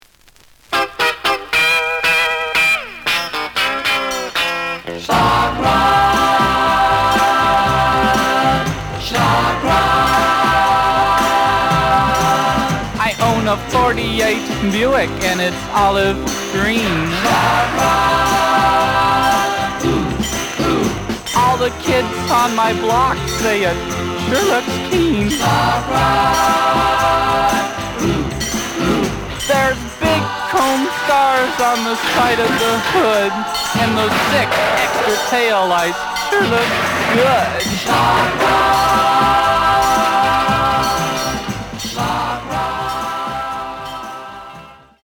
The audio sample is recorded from the actual item.
●Format: 7 inch
●Genre: Rock / Pop